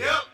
Tm8_Chant74.wav